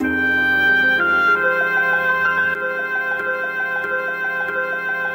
RANDOM LOOP.wav